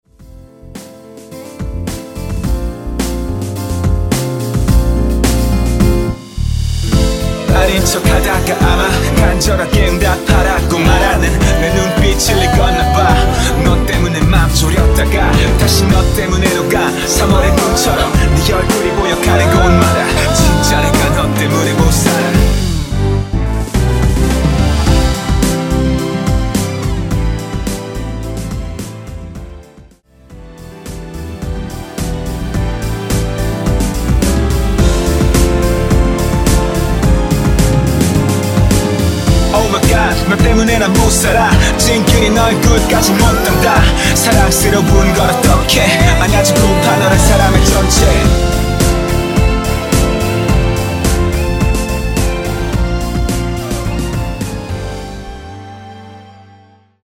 (-2) 내린 랩추가된 MR 입니다.(파란색 가사 부분과 미리듣기 참조 하세요)
◈ 곡명 옆 (-1)은 반음 내림, (+1)은 반음 올림 입니다.
앞부분30초, 뒷부분30초씩 편집해서 올려 드리고 있습니다.
중간에 음이 끈어지고 다시 나오는 이유는